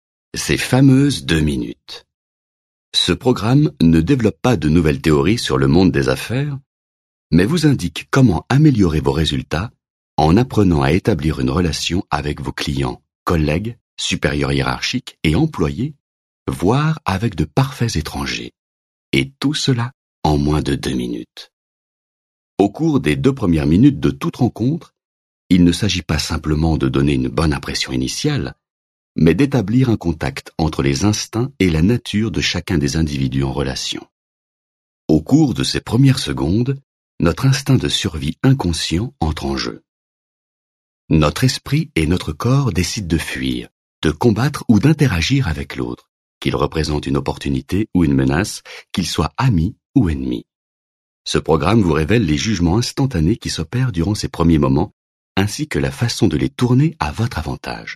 Click for an excerpt - Convaincre en moins de 2 min de Nicholas Boothman